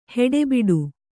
♪ heḍe biḍu